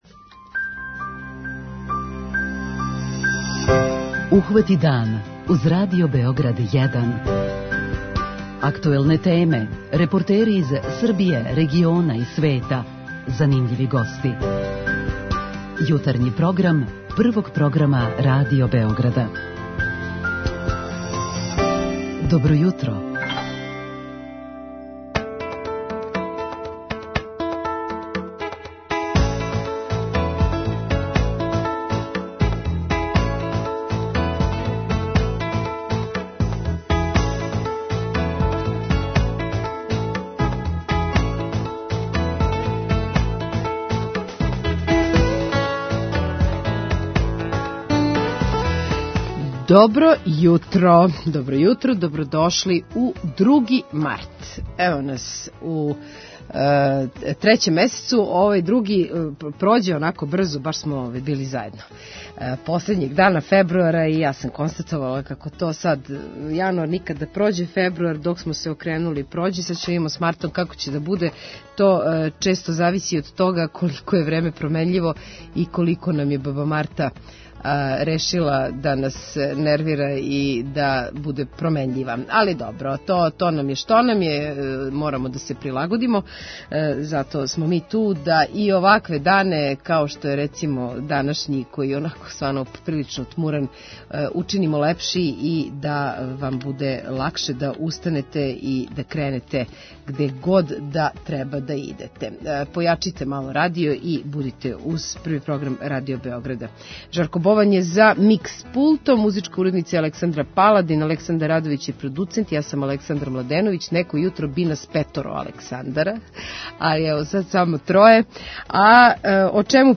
Тема ће бити и градски превоз као и најављене припреме за изградњу метро станица. преузми : 37.75 MB Ухвати дан Autor: Група аутора Јутарњи програм Радио Београда 1!